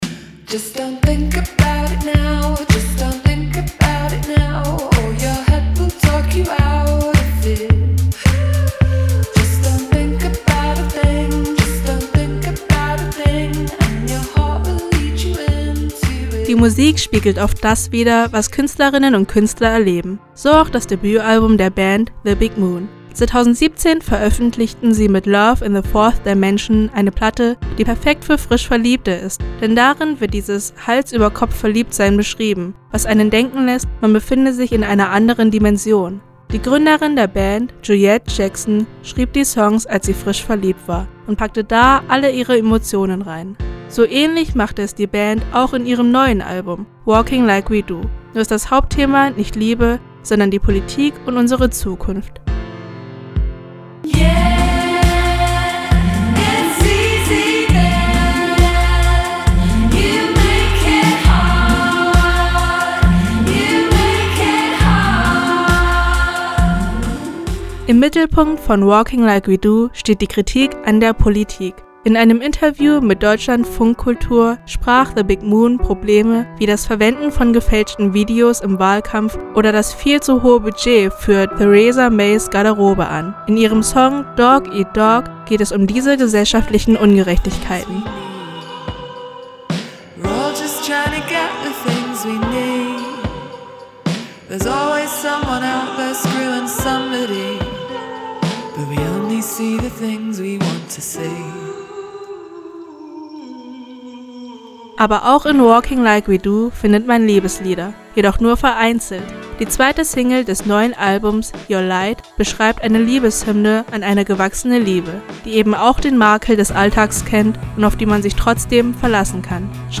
Indie-Rock